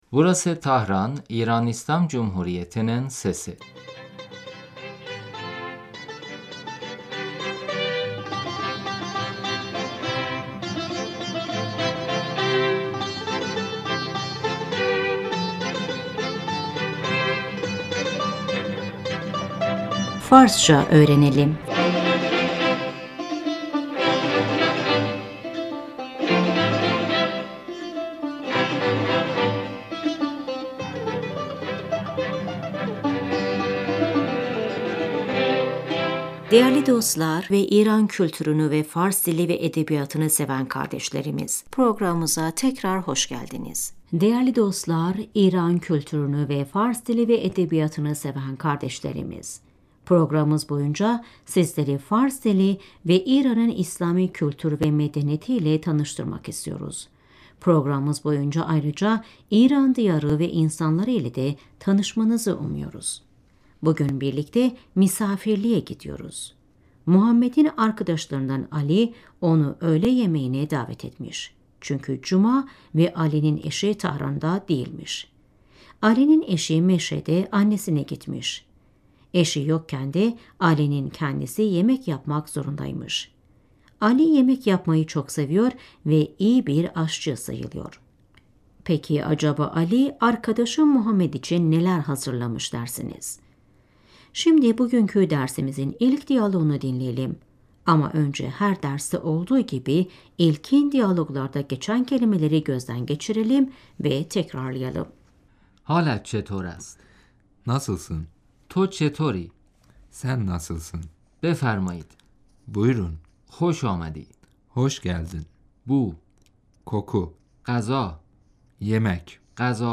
Şimdi bu diyaloğu dinleyin ve tekrarlayın.